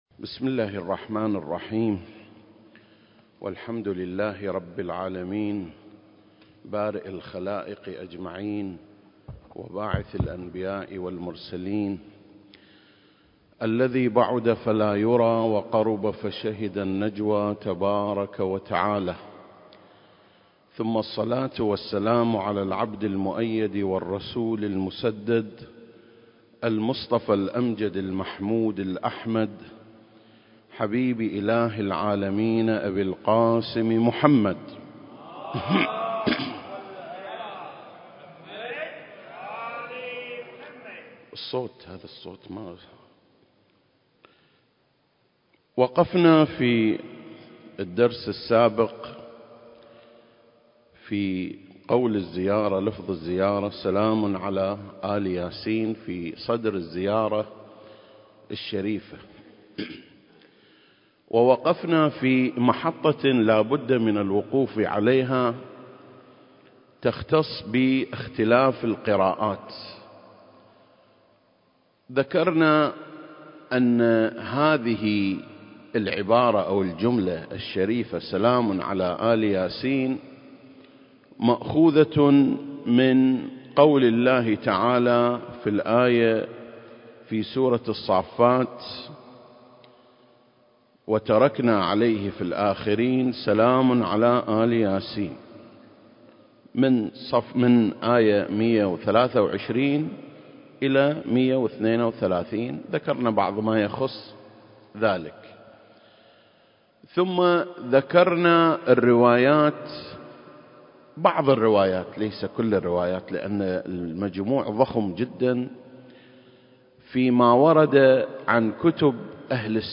سلسلة: شرح زيارة آل ياسين (21) - آل ياسين (2) المكان: مسجد مقامس - الكويت التاريخ: 2021